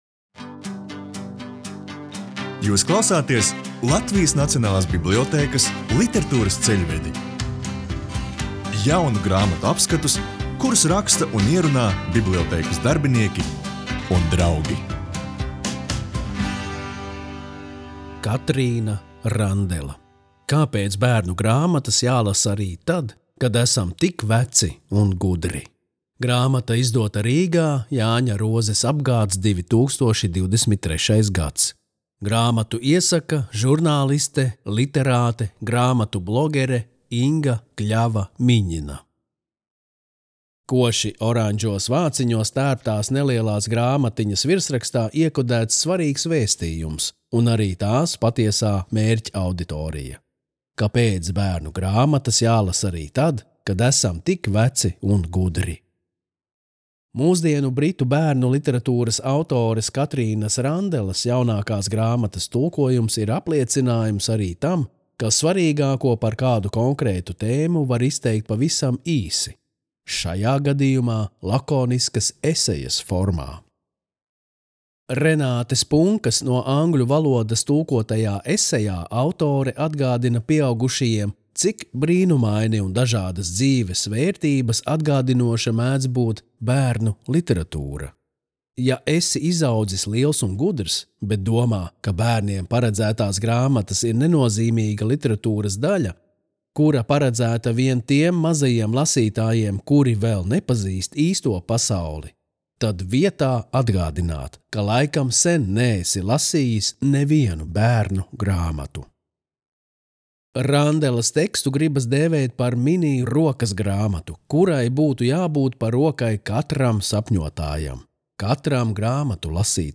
Latvijas Nacionālās bibliotēkas audio studijas ieraksti (Kolekcija)